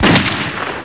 GUNSHOT1.mp3